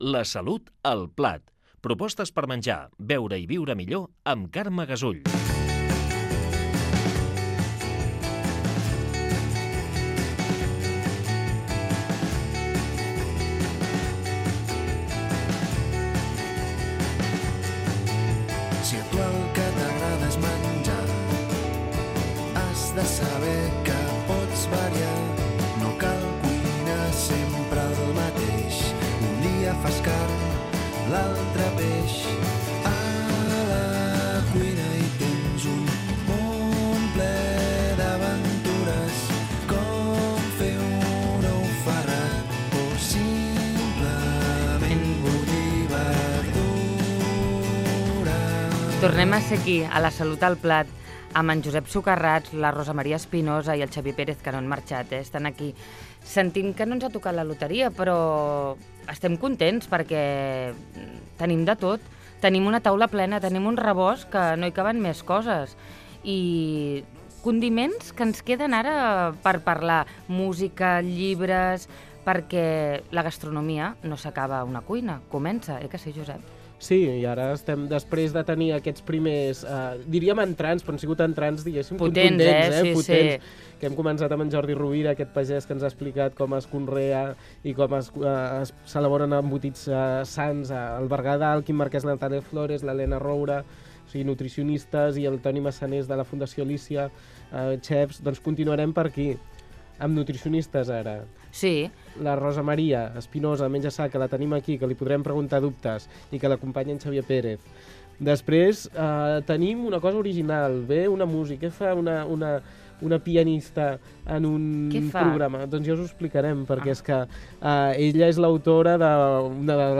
Careta del programa, equip i sumari
Divulgació
FM